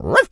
pgs/Assets/Audio/Animal_Impersonations/dog_small_bark_06.wav at master
dog_small_bark_06.wav